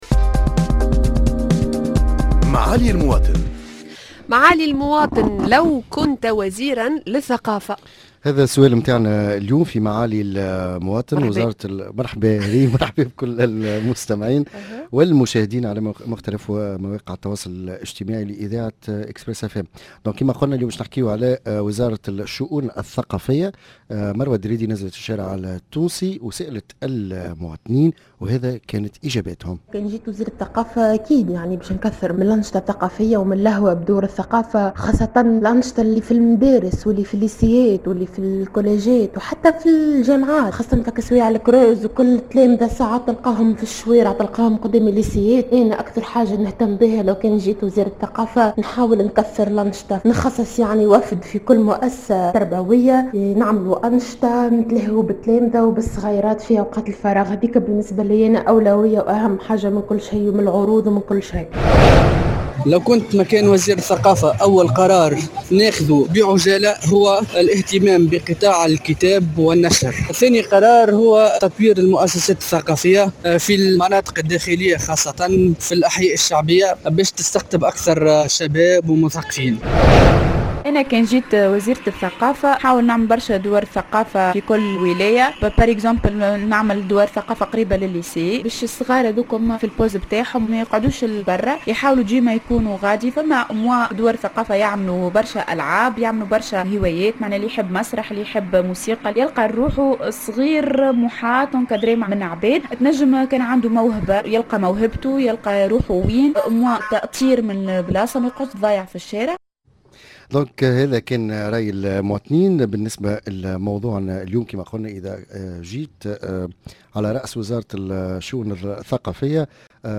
Micro Trottoir